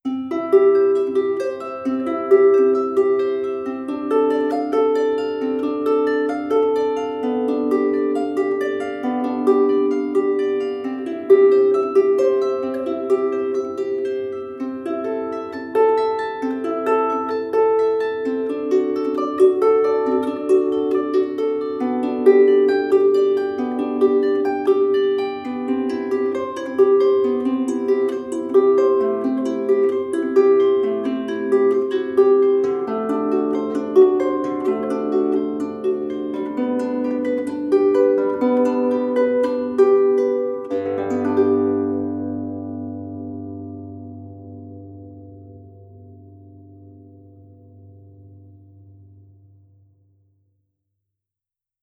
• titan natural harp classical sequence.wav
titan_natural_harp_classical_sequence_QhU.wav